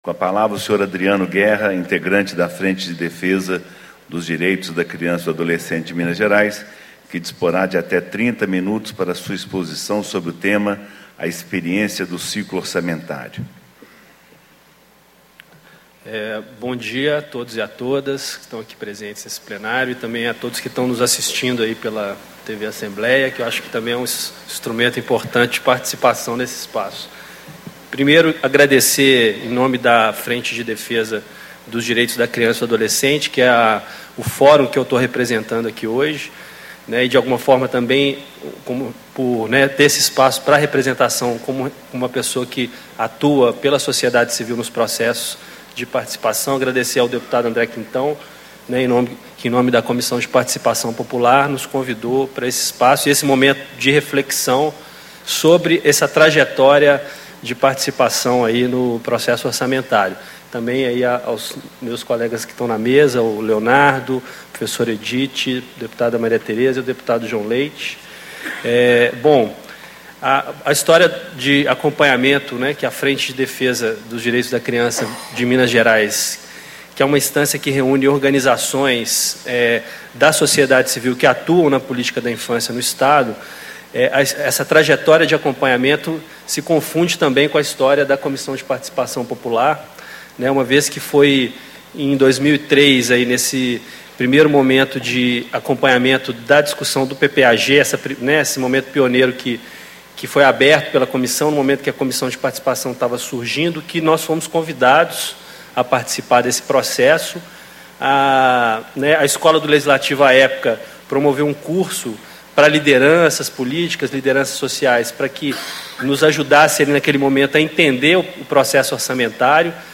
Palestra